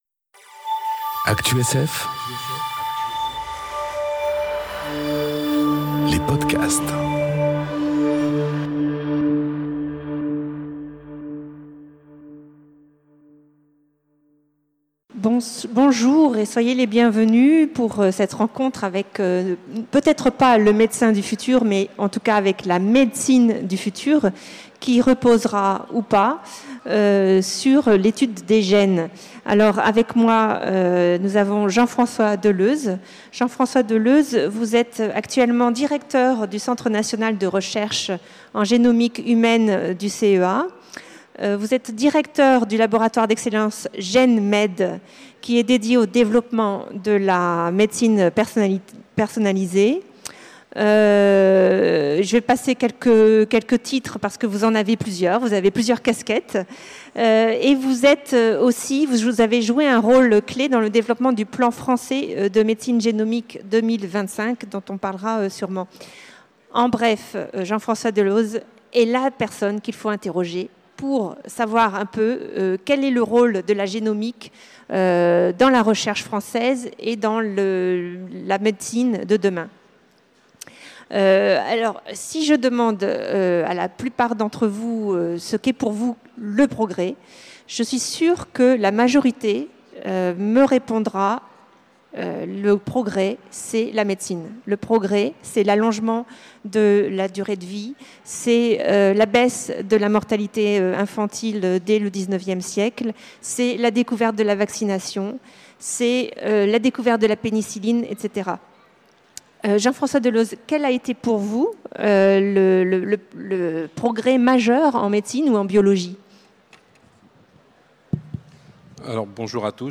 Conférence Rencontre avec le médecin du futur enregistrée aux Utopiales 2018